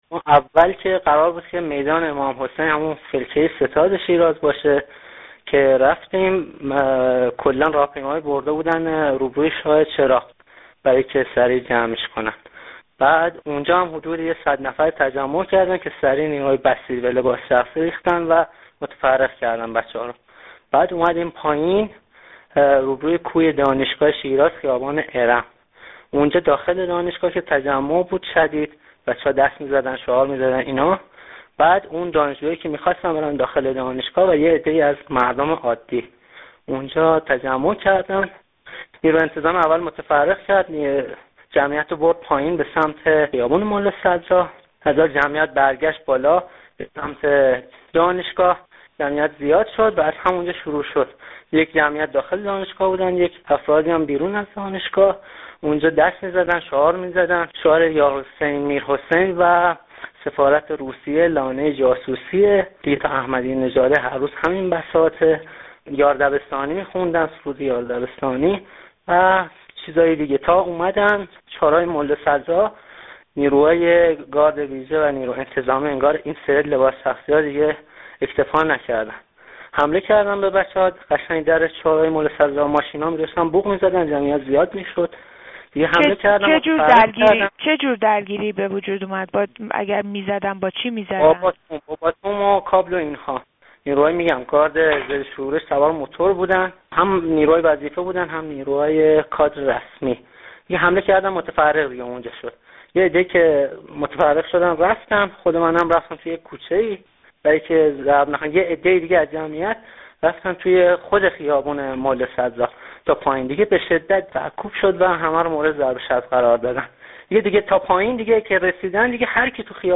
گفت‌وگو با یک شاهد عینی دیگر در مورد حال و هوای امروز شیراز